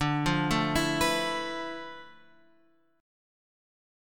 Dm6add9 chord {10 8 7 9 x 7} chord